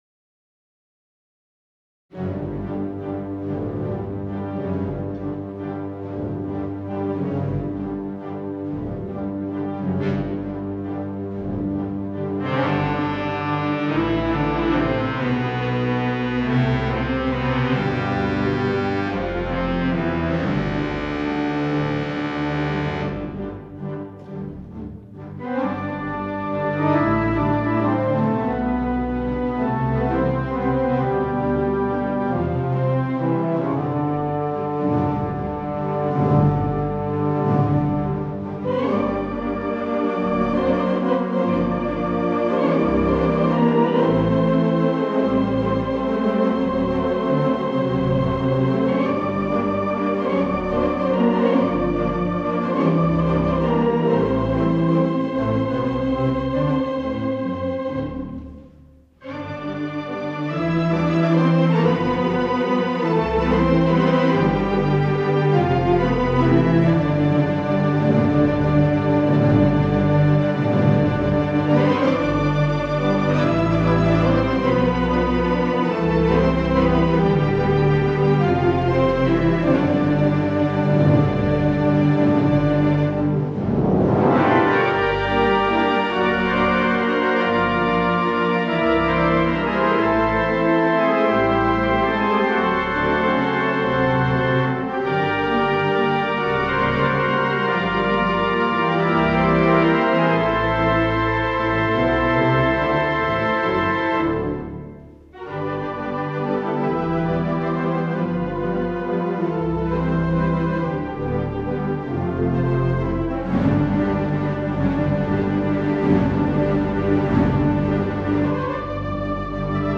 Theatre Pipe Organ